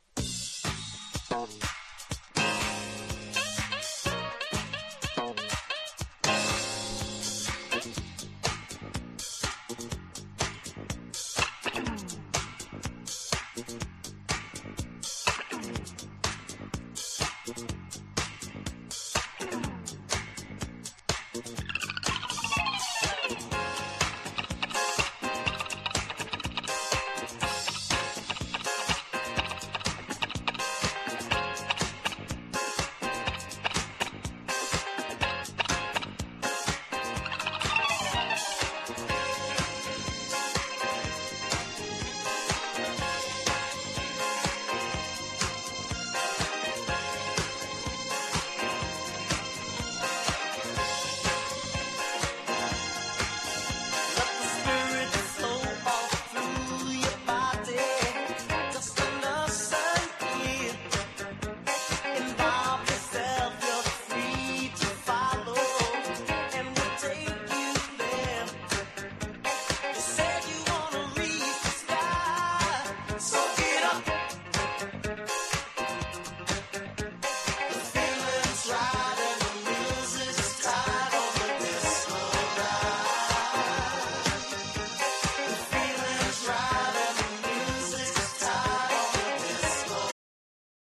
• DANCE CLASSICS
(断続的に薄くチリノイズ入ります)